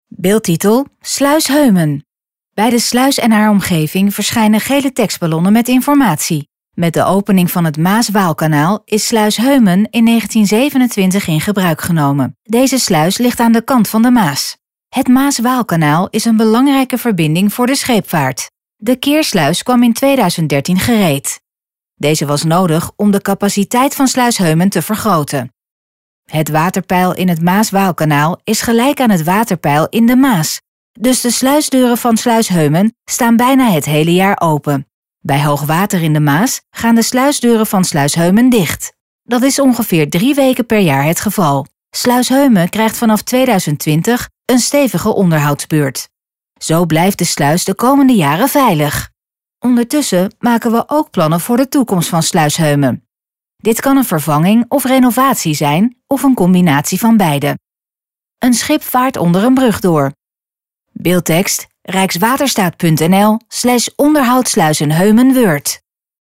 RUSTIGE MUZIEK TOT HET EIND VAN DE VIDEO (Het Maas-Waalkanaal is een belangrijke verbinding voor de scheepvaart.